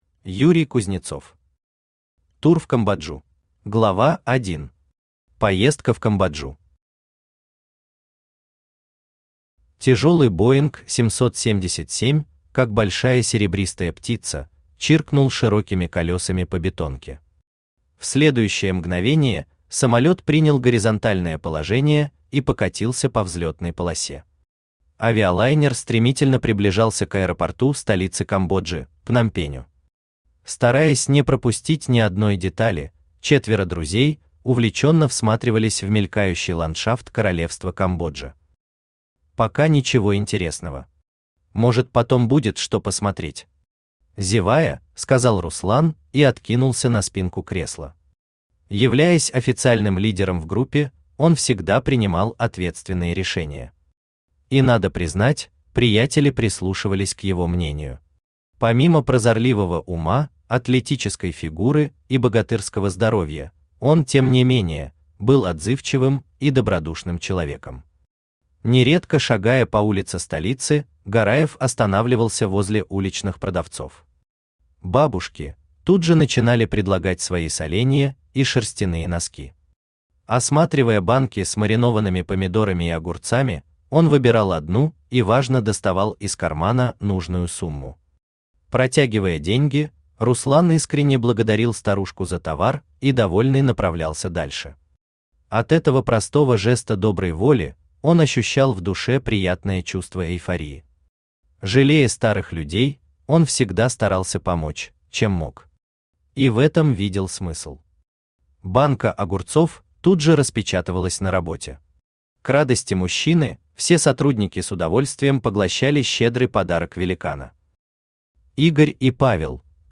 Аудиокнига Тур в Камбоджу | Библиотека аудиокниг
Aудиокнига Тур в Камбоджу Автор Юрий Юрьевич Кузнецов Читает аудиокнигу Авточтец ЛитРес.